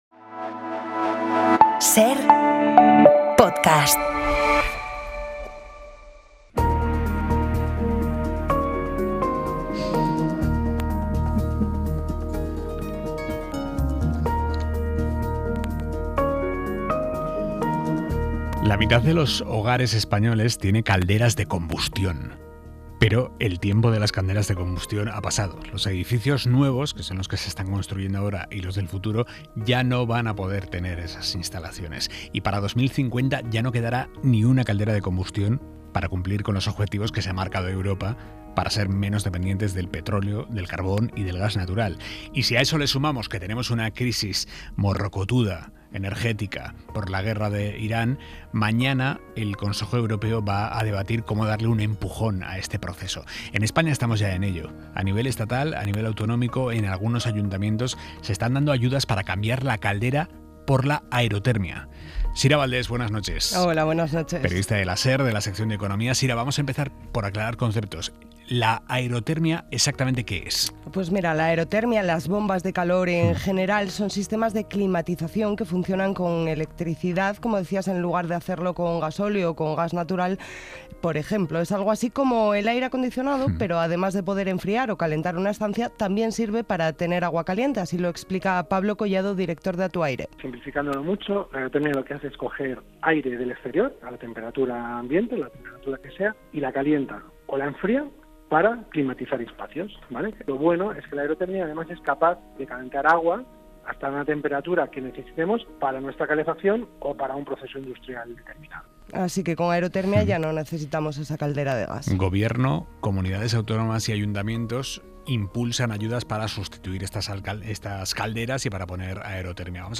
Hablamos de ello con la periodista